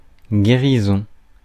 Ääntäminen
US : IPA : [ˈhi.lɪŋ]